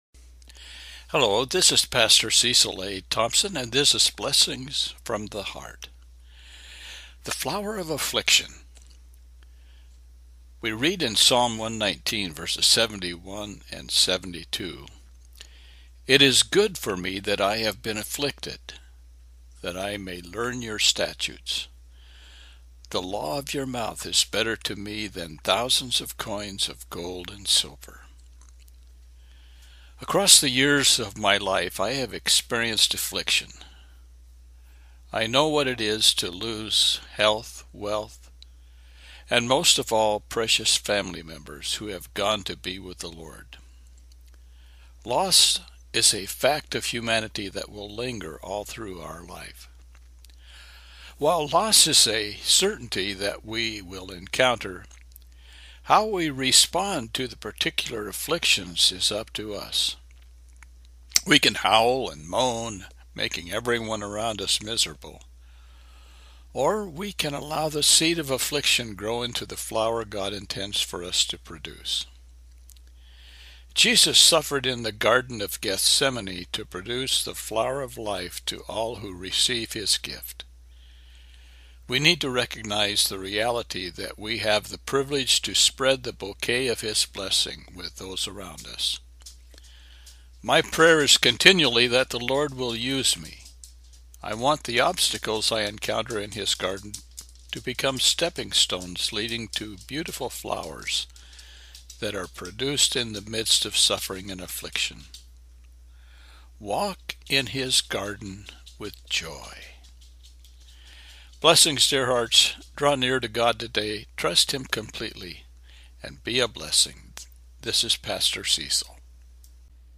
Psalm 119:71-72 – Devotional